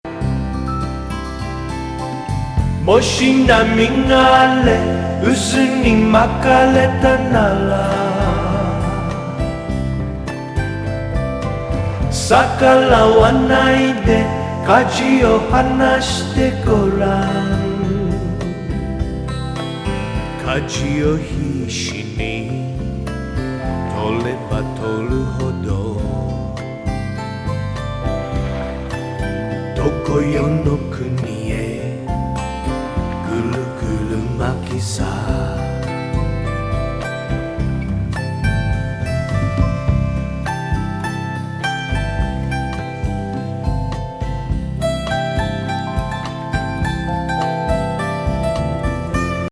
和エスノ・ムード全開のニューエイジ・フュージョン・ロック作!
メロウSSW